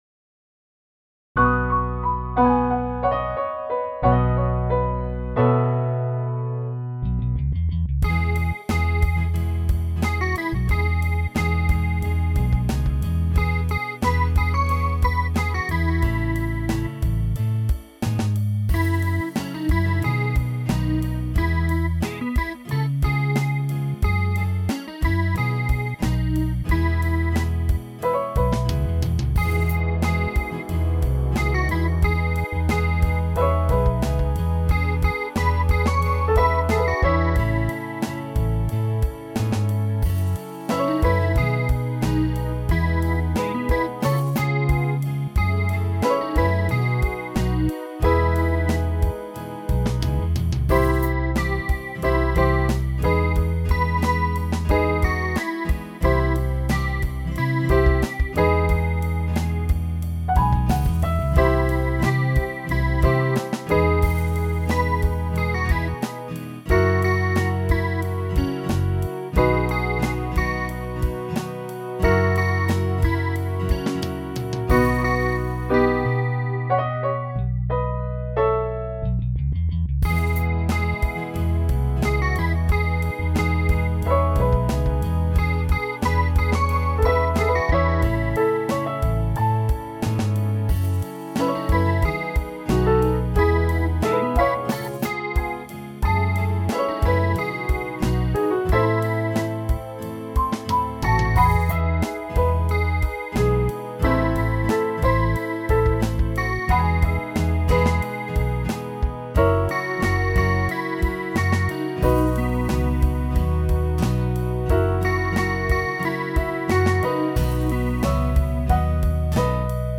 Index of /SONG-FOR-ANYONE/EN MI MAJEUR
Mi-avec-chant.mp3